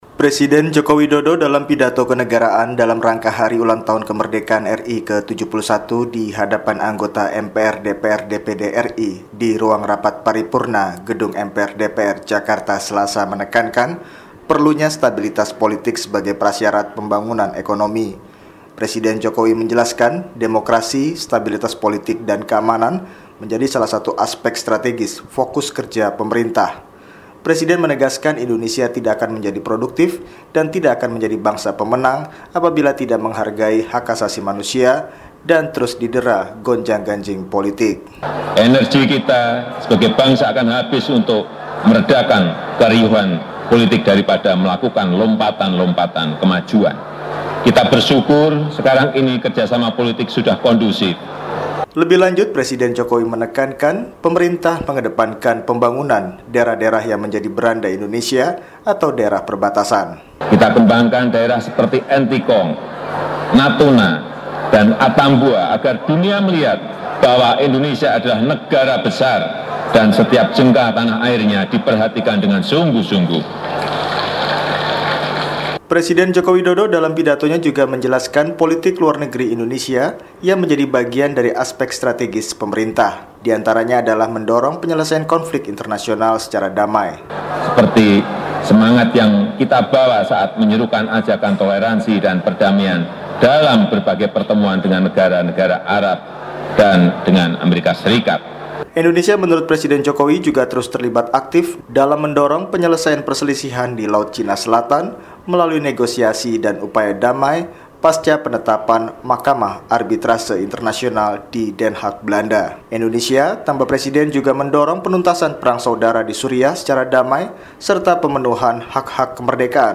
Presiden Joko Widodo dalam pidato kenegaraan di gedung parlemen menekankan pentingnya demokrasi, stabilitas politik dan keamanan nasional yang menjadi salah satu fokus kerja pemerintah.
Pidato Kenegaraan Presiden Joko Widodo